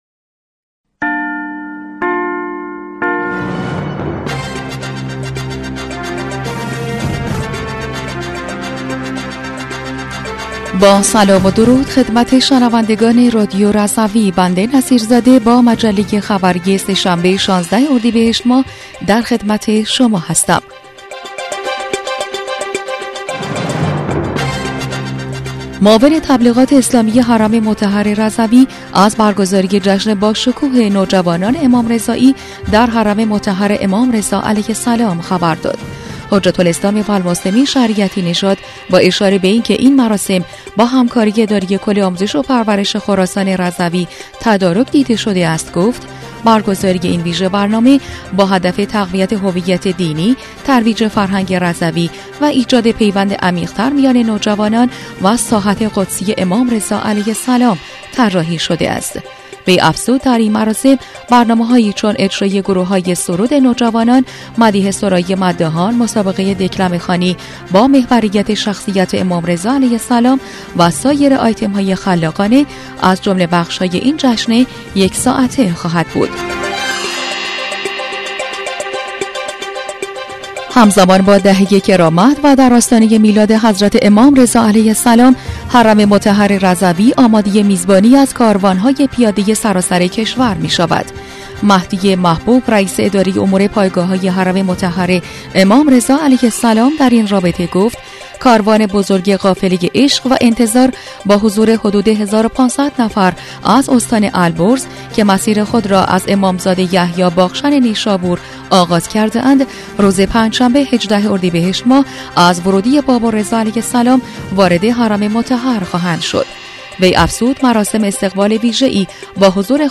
بسته خبری ۱۶ اردیبهشت رادیو رضوی؛